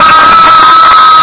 jumpscare.mp3